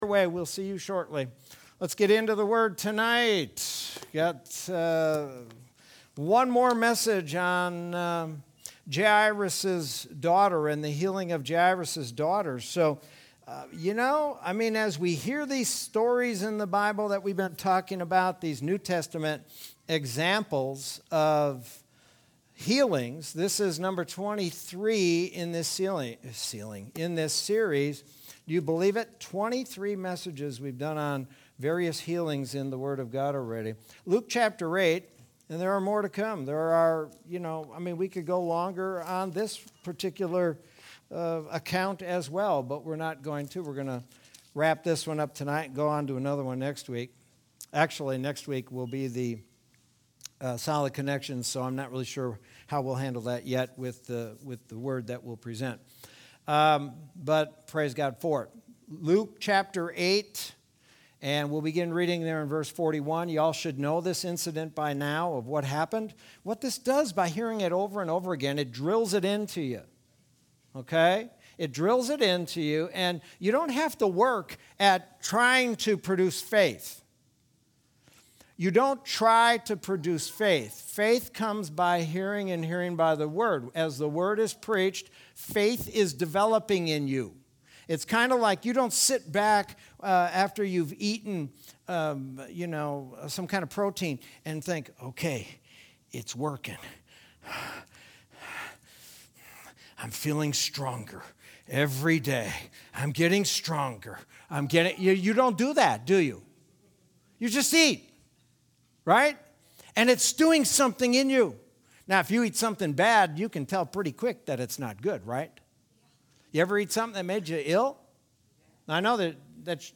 Sermon from Wednesday, July 21st, 2021.